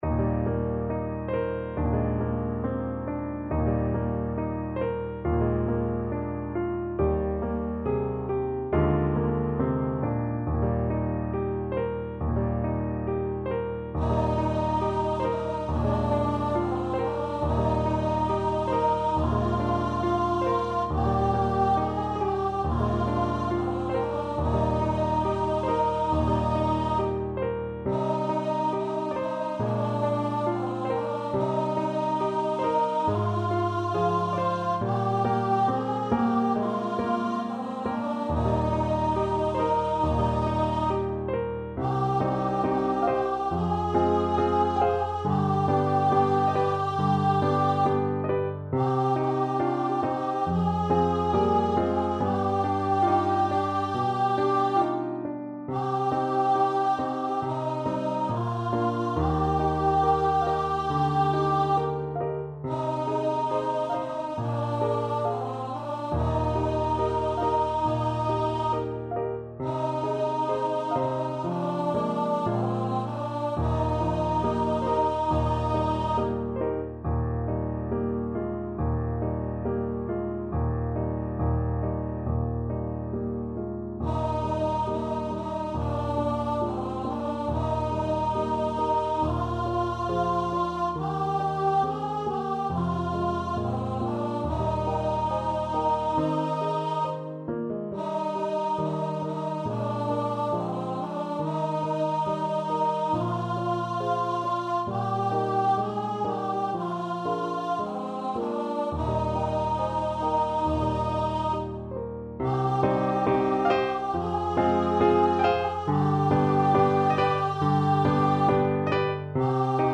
Voice
Eb major (Sounding Pitch) (View more Eb major Music for Voice )
~ = 69 Andante tranquillo
4/4 (View more 4/4 Music)
Classical (View more Classical Voice Music)